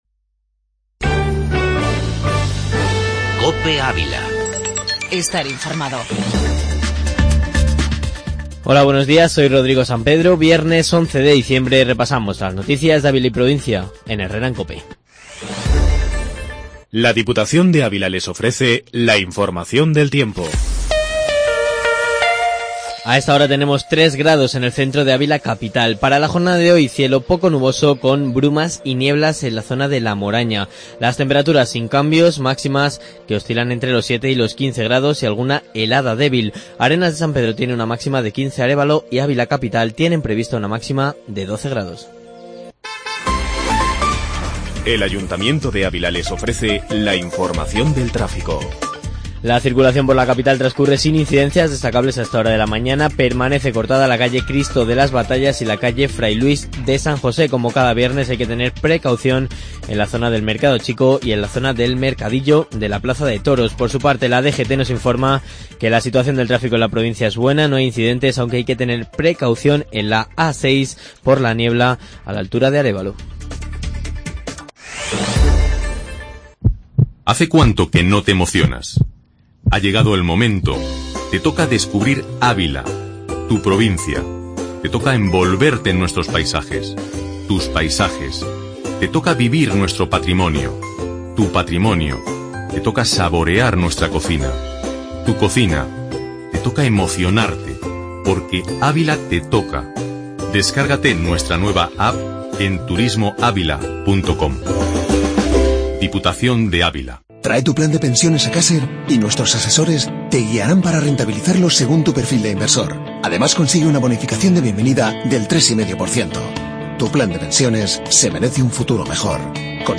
Informativo matinal en 'Herrera en COPE'.